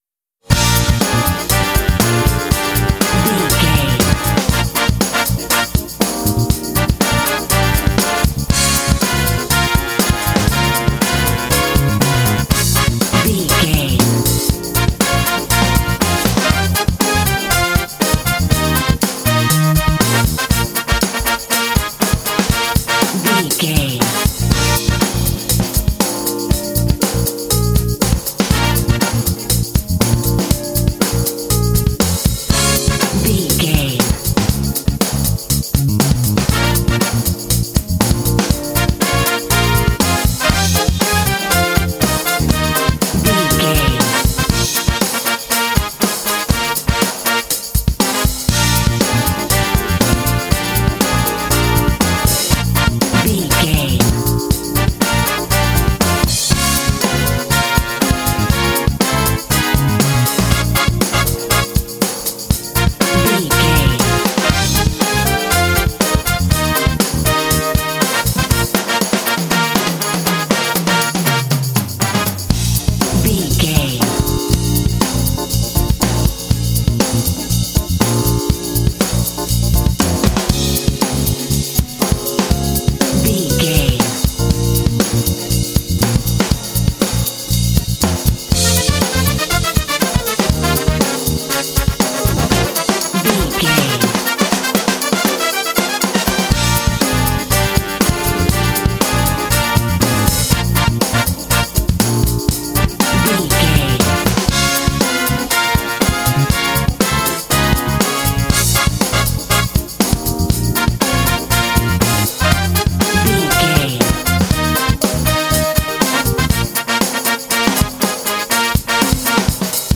Uplifting
Aeolian/Minor
B♭
groovy
funky
cheerful/happy
driving
brass
bass guitar
saxophone
electric organ
drums
percussion
jazz